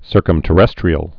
(sûrkəm-tə-rĕstrē-əl)